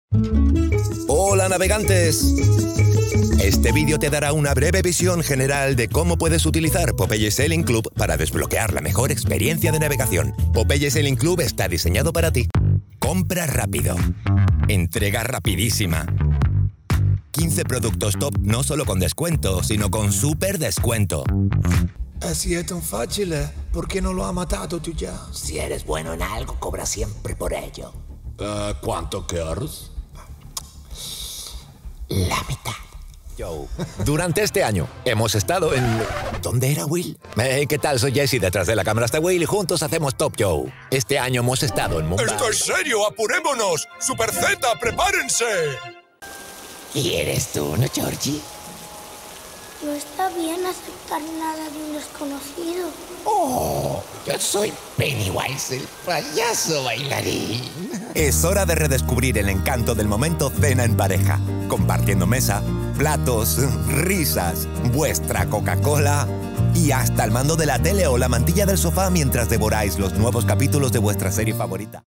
Me presento: soy español nativo y vivo en Sevilla (castellano neutro).
Autoritario
Corporativo
Oscuro